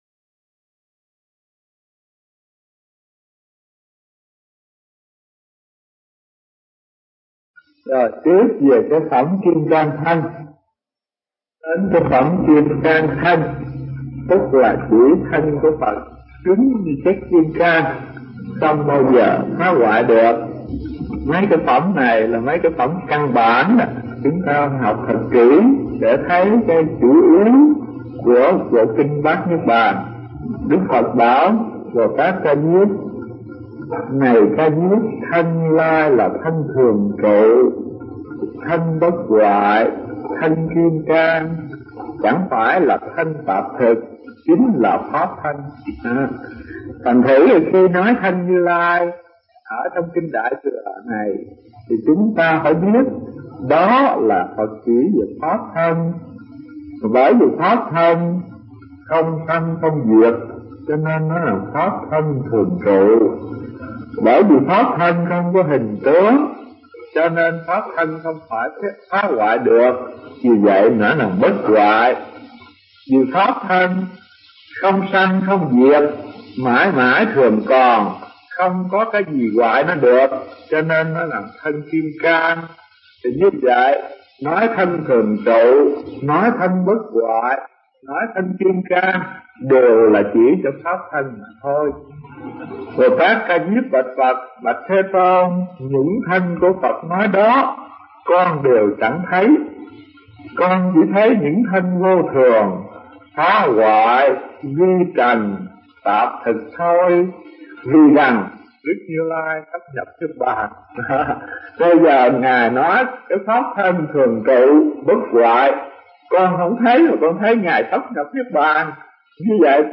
Tải mp3 Pháp Âm Kinh Đại Bát Niết Bàn 05 – Kim Cang Thân – Hòa Thượng Thích Thanh Từ